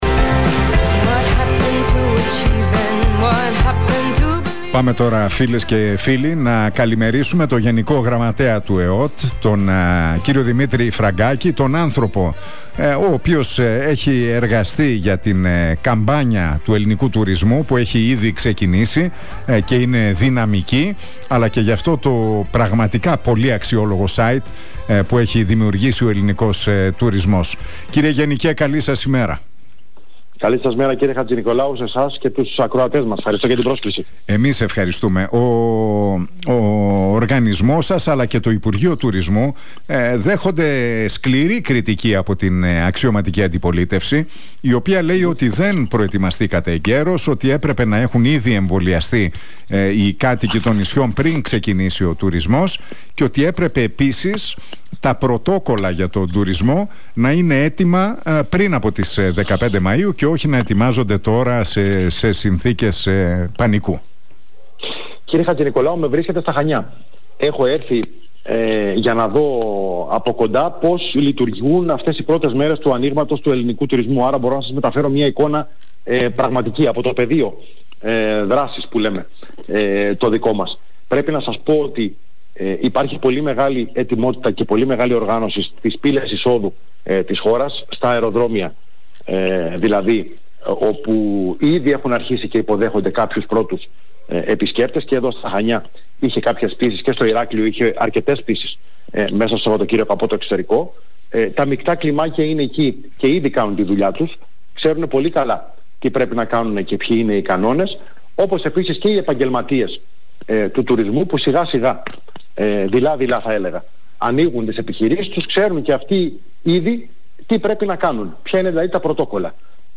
Ο γενικός γραμματέας του ΕΟΤ, Δημήτρης Φραγκάκης, μιλώντας στον Realfm 97,8 και την εκπομπή του Νίκου Χατζηνικολάου...